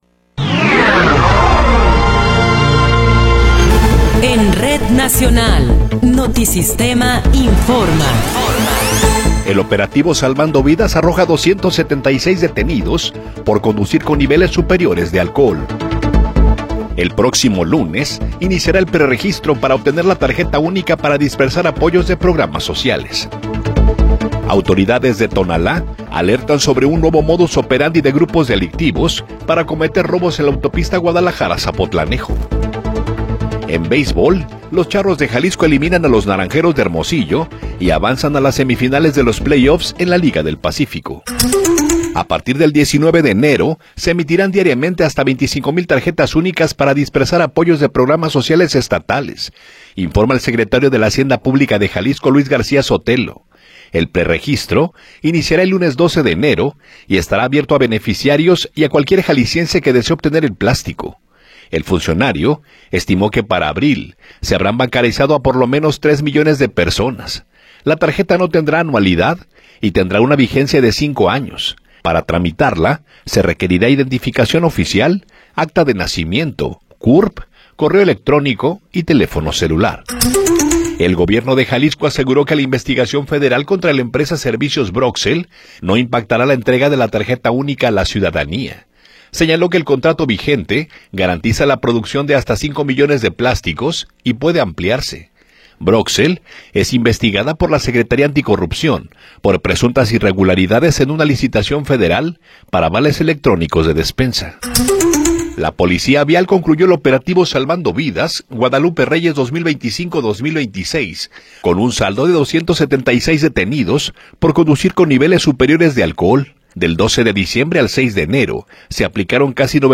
Noticiero 9 hrs. – 9 de Enero de 2026
Resumen informativo Notisistema, la mejor y más completa información cada hora en la hora.